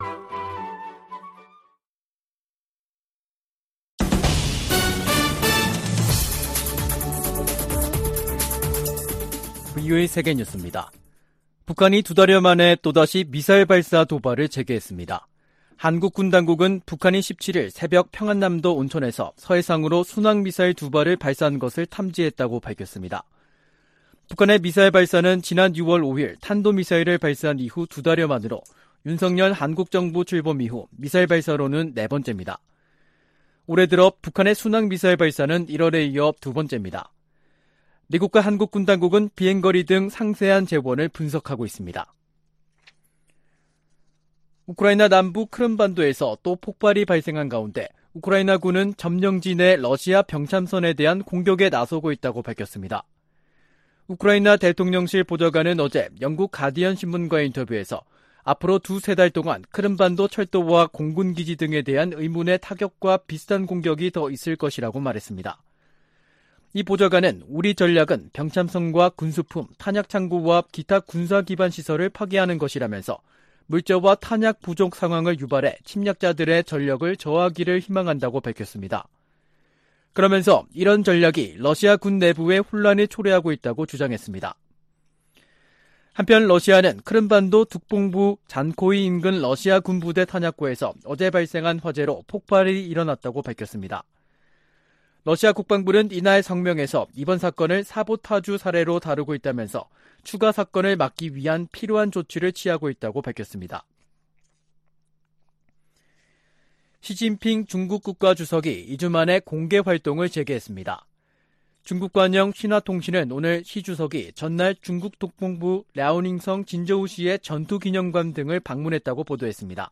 VOA 한국어 간판 뉴스 프로그램 '뉴스 투데이', 2022년 8월 17일 2부 방송입니다. 북한이 두 달여 만에 미사일 발사 도발을 재개했습니다. 윤석열 한국 대통령은 광복절 경축사에서 밝힌 담대한 구상 대북 제안은 북한이 비핵화 의지만 보여주면 적극 돕겠다는 것이라며 북한의 호응을 촉구했습니다. 미 국무부는 미국과 한국의 연합 군사훈련이 한국 안보를 지키기 위한 순수한 방어 목적임을 강조했습니다.